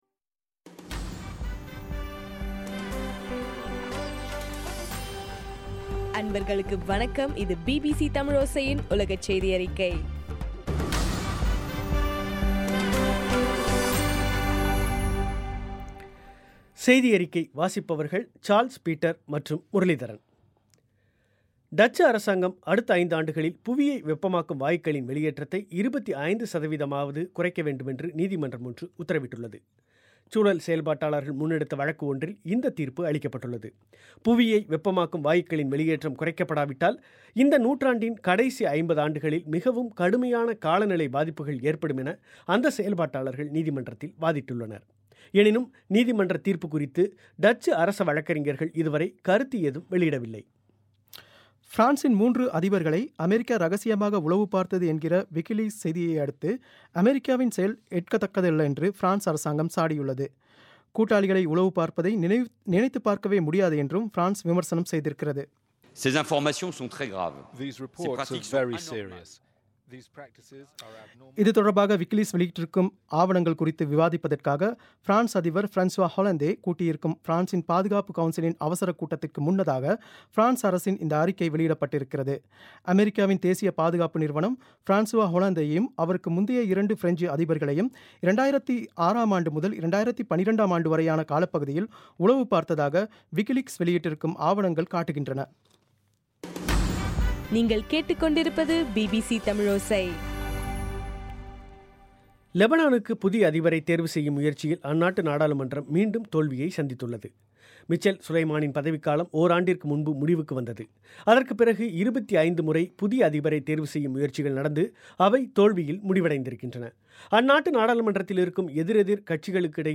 ஜூன் 24 2015 பிபிசி தமிழோசையின் உலகச் செய்திகள்